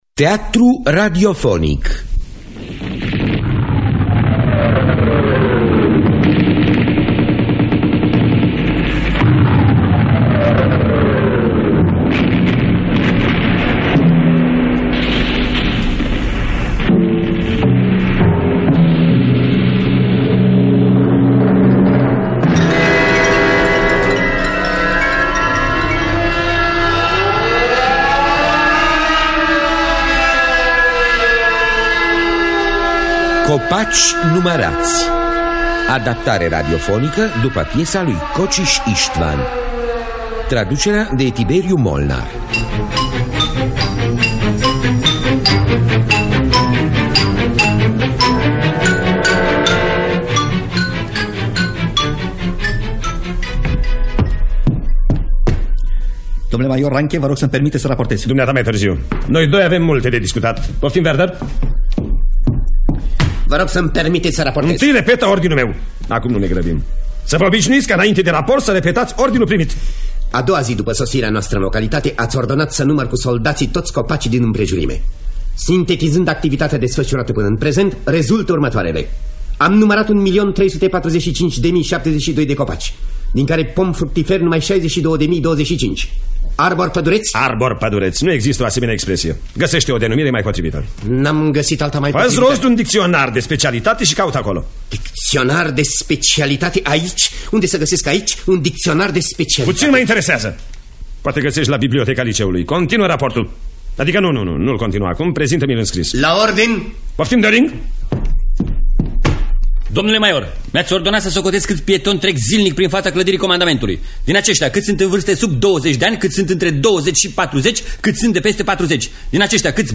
Copaci numărați de Istvan Kocsis – Teatru Radiofonic Online